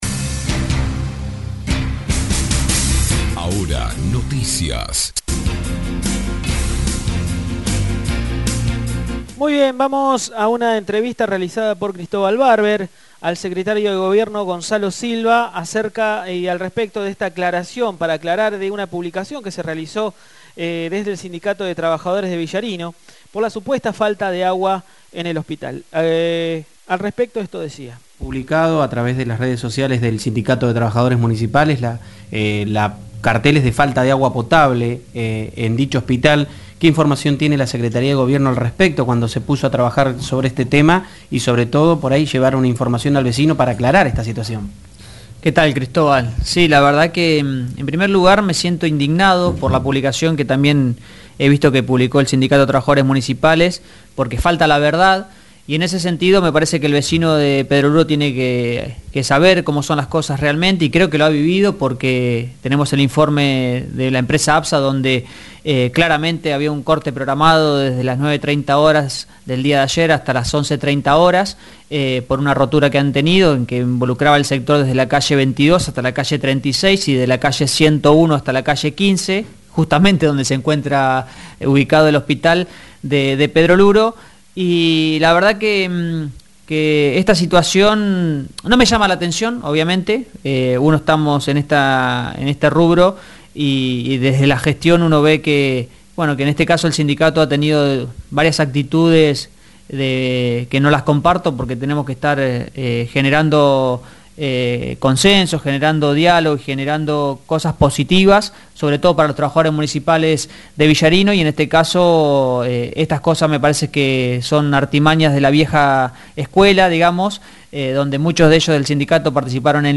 Así lo manifestó el Secretario del Gobierno Municipal, Gonzalo Silva , quien habló sobre la polémica que generó el posteo del sindicato de trabajadores municipales de Villarino, manifestado que el hospital de Pedro Luro no tenía agua potable.
“Me llama la atención estas cuestiones sobre todo la mala fé” comentó Silva en la entrevista.